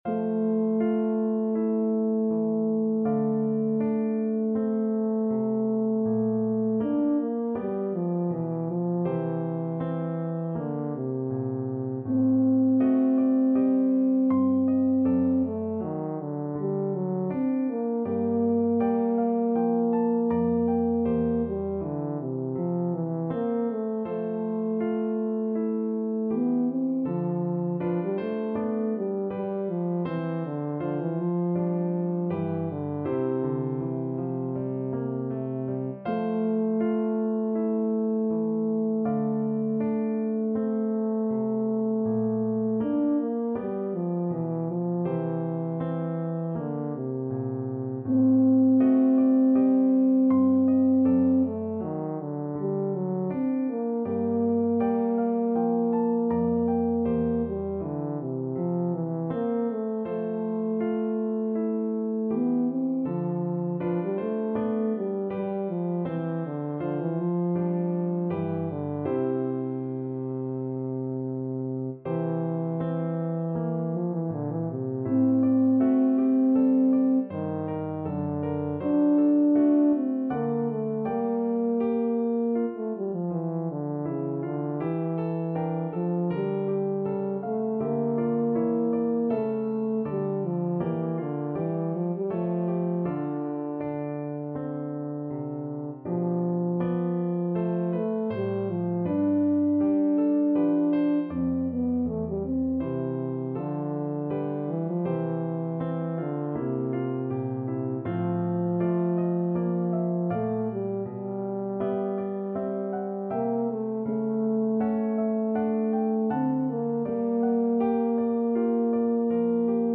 Baritone Horn
F major (Sounding Pitch) (View more F major Music for Baritone Horn )
Andante
Classical (View more Classical Baritone Horn Music)